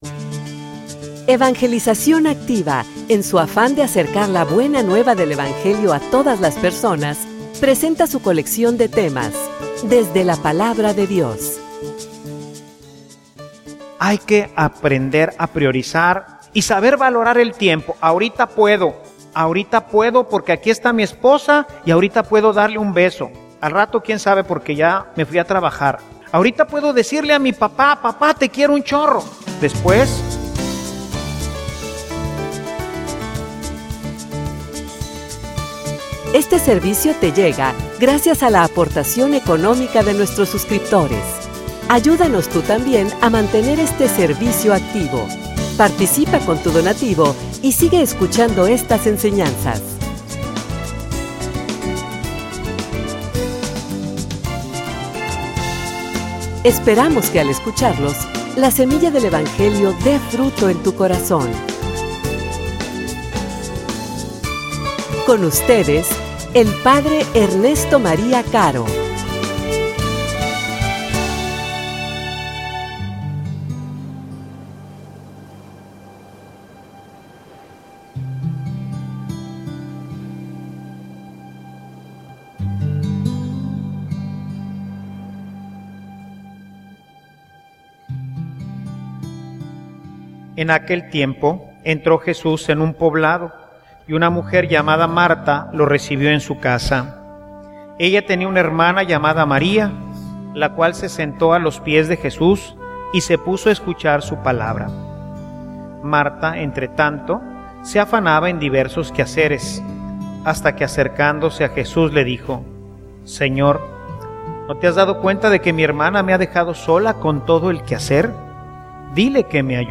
homilia_Aprovecha_el_momento.mp3